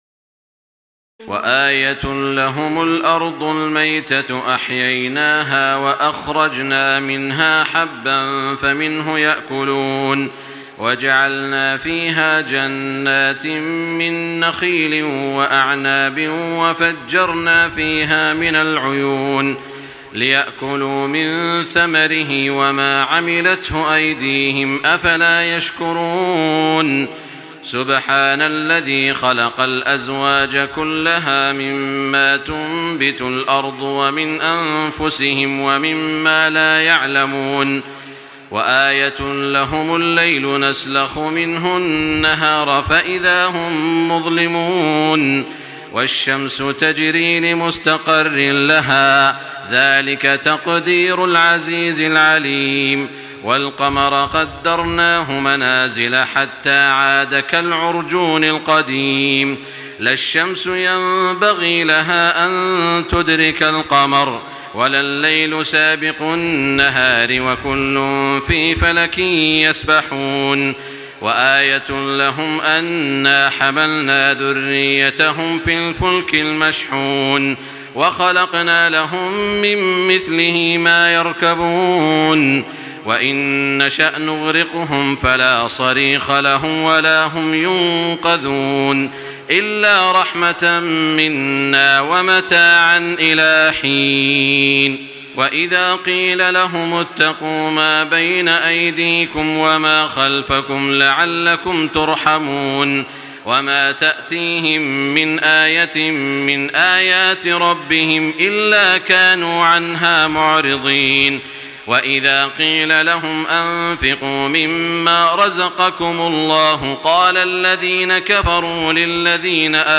تراويح ليلة 22 رمضان 1425هـ من سور يس (33-83) والصافات (1-138) Taraweeh 22 st night Ramadan 1425H from Surah Yaseen and As-Saaffaat > تراويح الحرم المكي عام 1425 🕋 > التراويح - تلاوات الحرمين